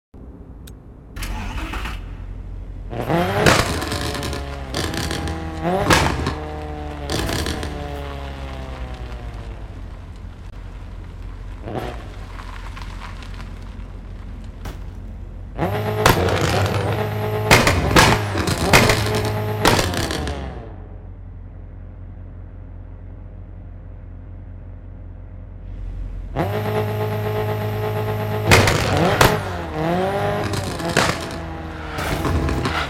Mp3 Sound Effect
honda civic ek9 real sound in car parking multiplayer 2